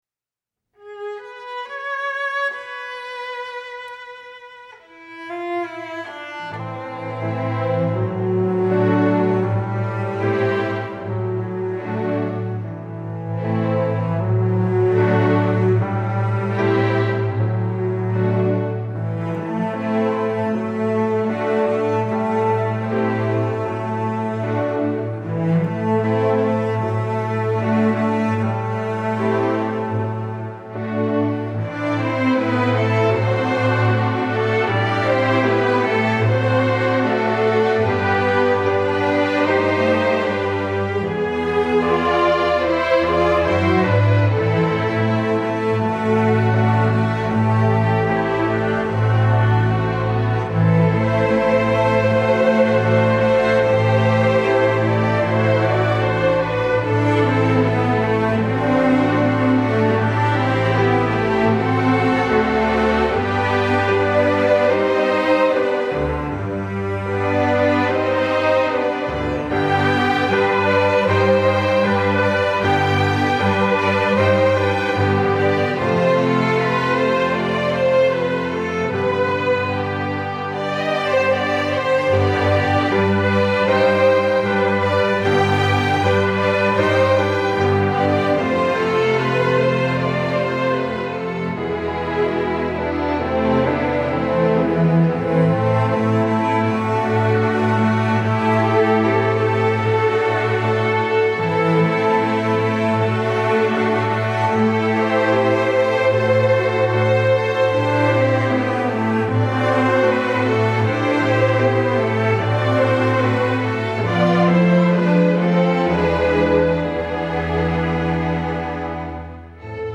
Besetzung: Streichorchester